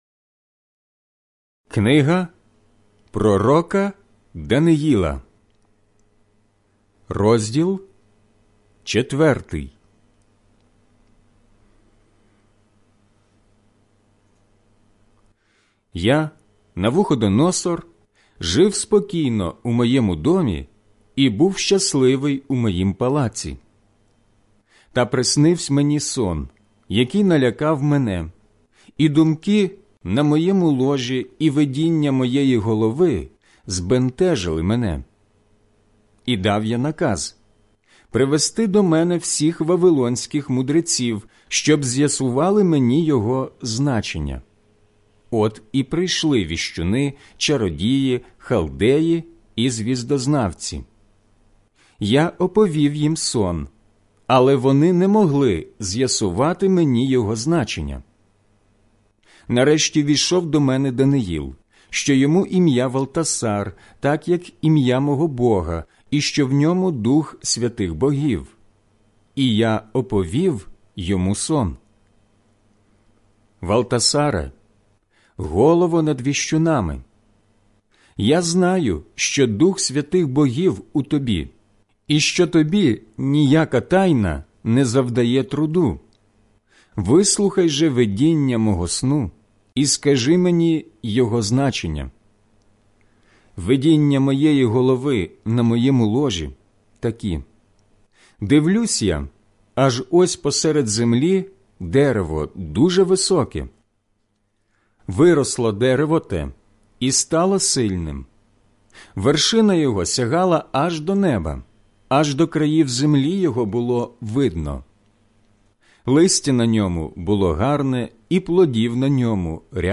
Інші статті за темами СЮЖЕТ аудіобіблія ПЕРСОНА МІСЦЕ ← Натисни «Подобається», аби читати CREDO в Facebook